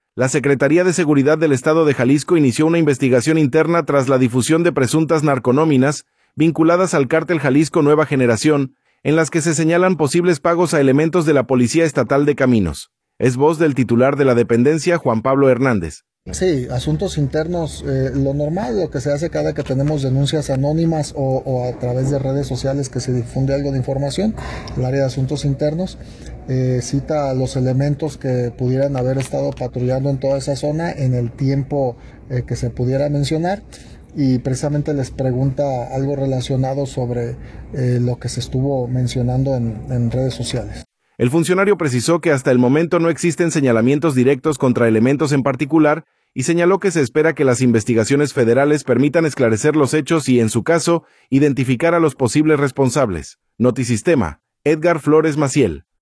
audio La Secretaría de Seguridad del Estado de Jalisco inició una investigación interna tras la difusión de presuntas “narconóminas” vinculadas al Cártel Jalisco Nueva Generación, en las que se señalan posibles pagos a elementos de la Policía Estatal de Caminos. Es voz del titular de la dependencia, Juan Pablo Hernández.